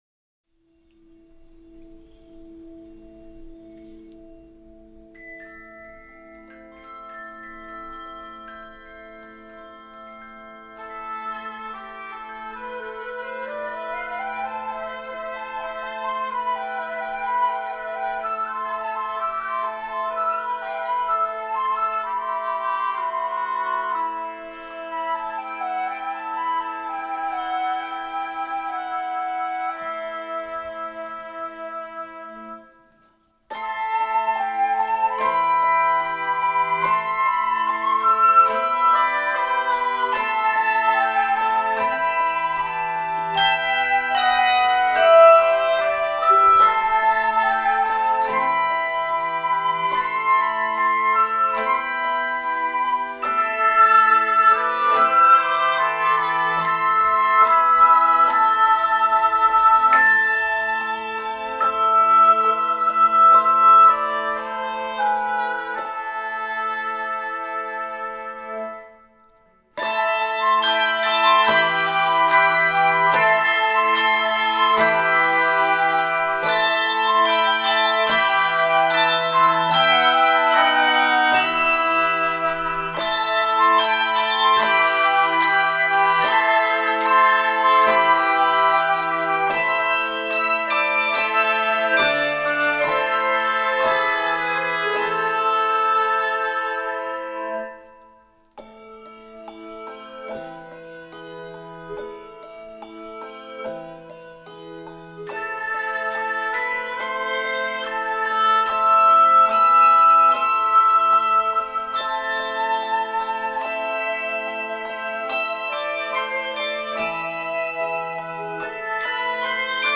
handbell soloist and keyboard accompaniment